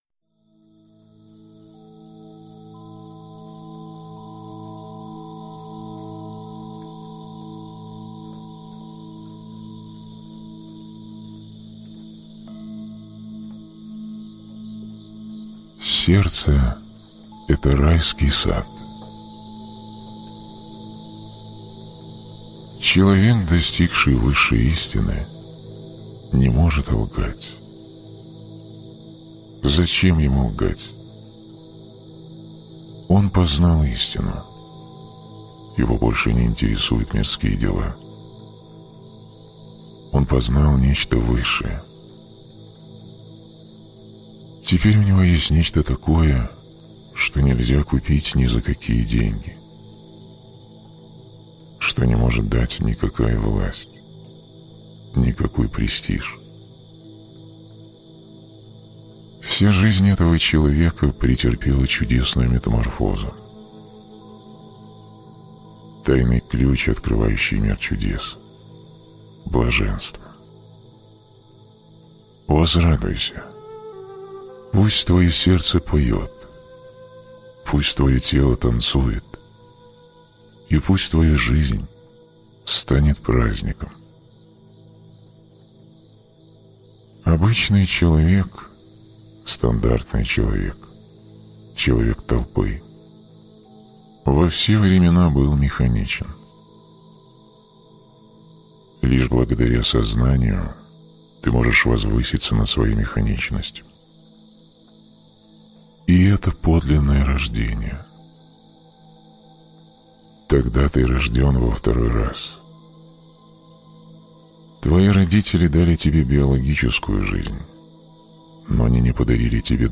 meditaciya.mp3